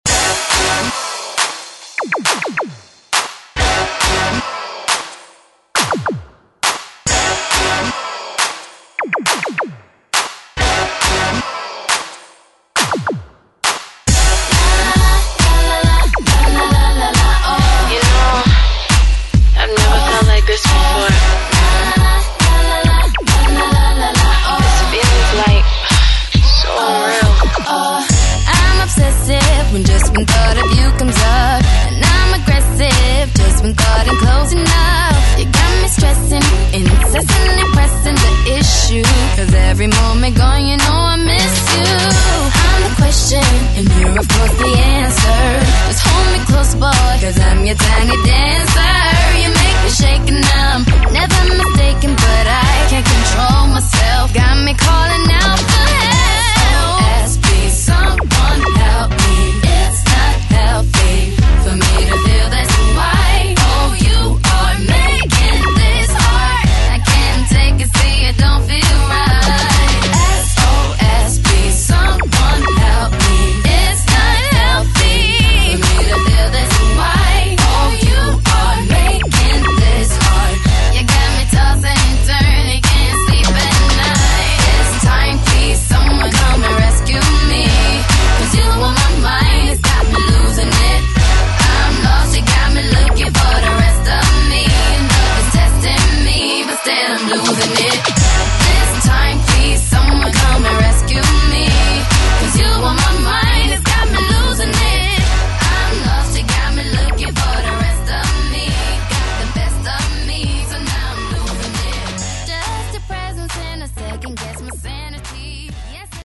Electronic Euro Dance Music
Extended Intro Outro
134 bpm
90's , DANCE , EDM